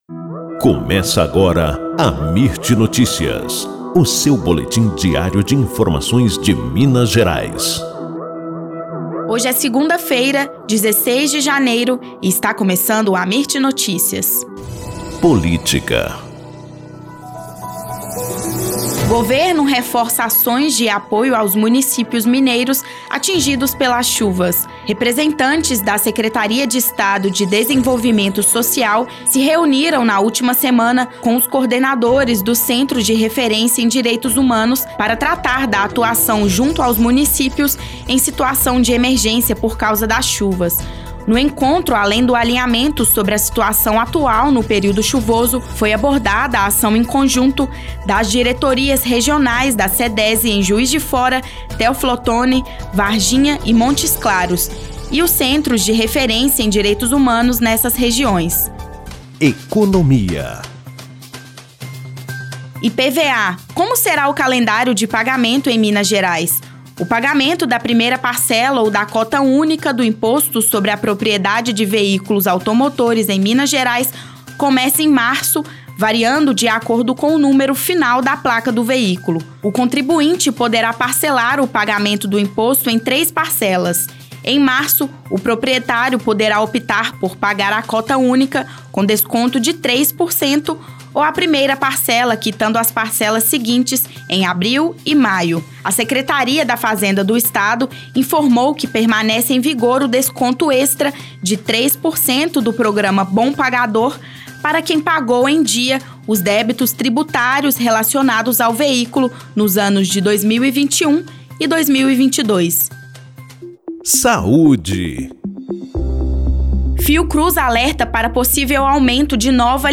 Boletim Amirt Notícias – 16 de janeiro